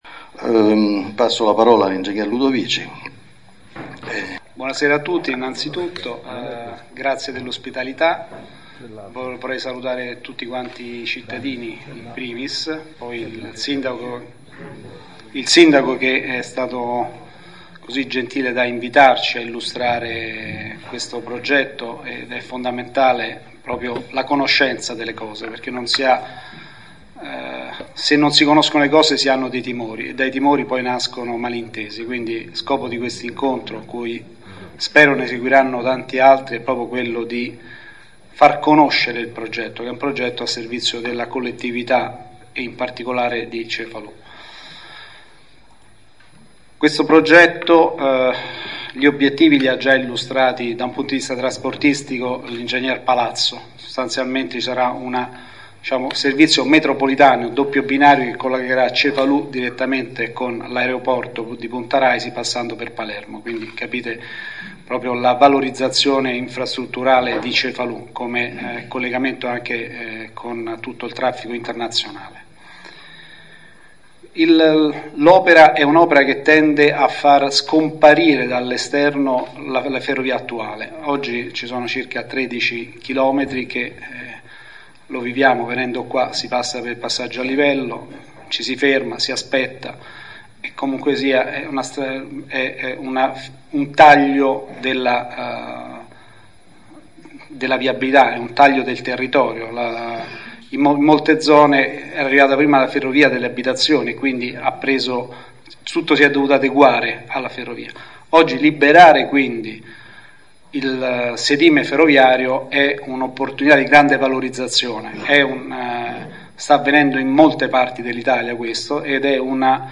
Si è svolto  a Cefalù giovedì 26 novembre alle ore 16, presso la sala consiliare del comune di Cefalù, il primo degli incontri preannunciati dal Sindaco con i vertici delle società interessate alla realizzazione dei lavori del raddoppio della linea ferroviaria e della costruzione della nuova stazione ferroviaria di Cefalù.